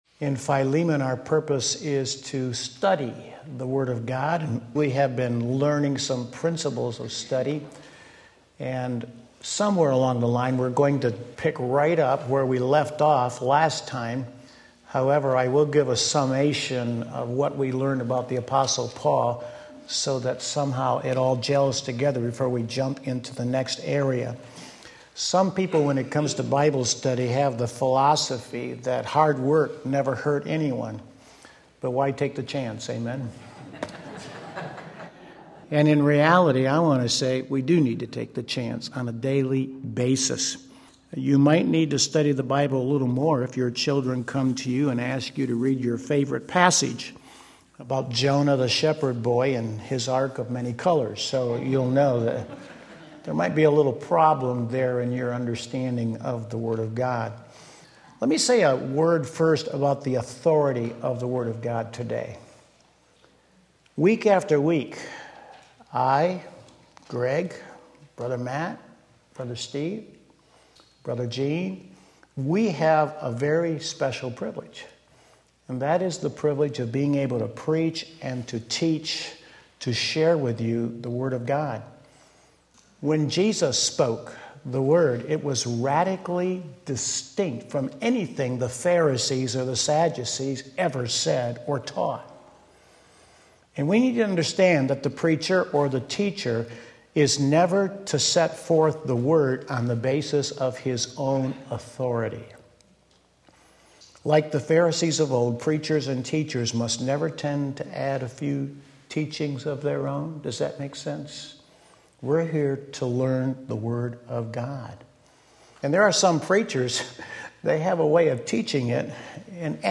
Sunday School